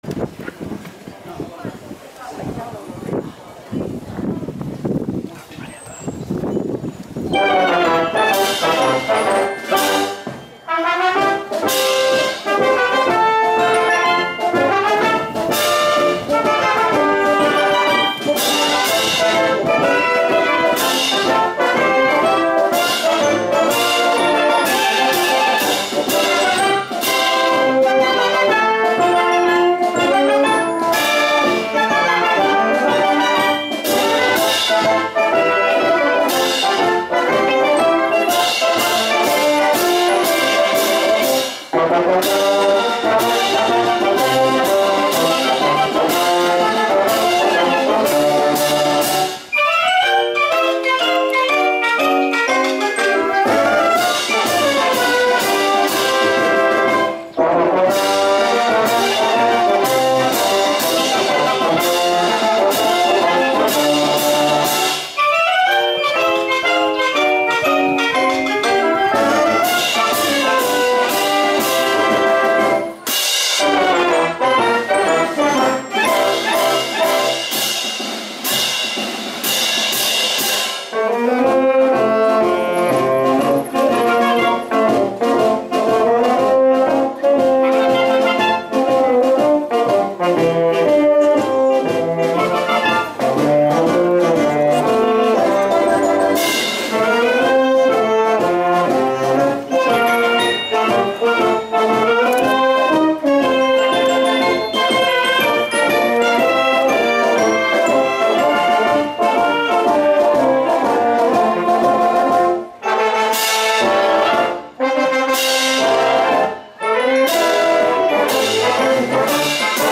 吹奏楽部が十三東商店街で演奏しました。
演目は「時代劇スぺシャル」 地域の方々にもおなじみの曲を、元気いっぱい演奏してくれました。